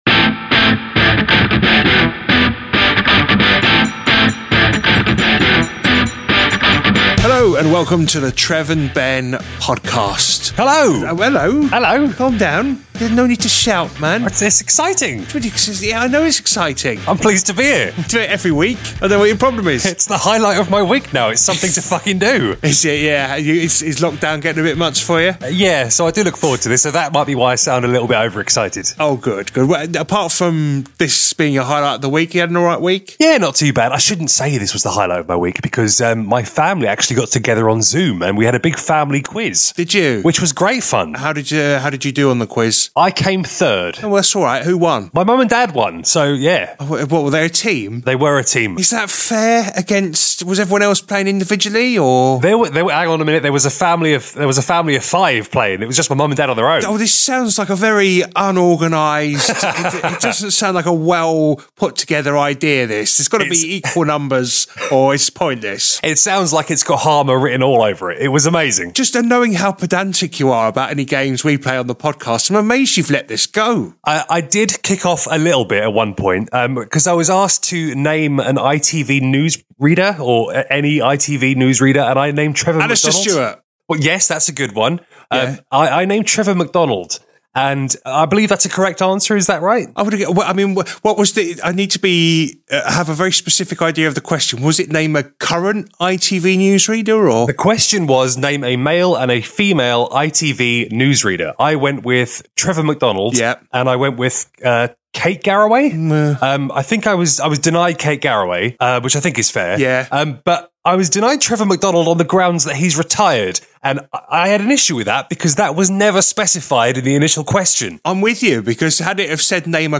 This week we're joined by special guest, voice actor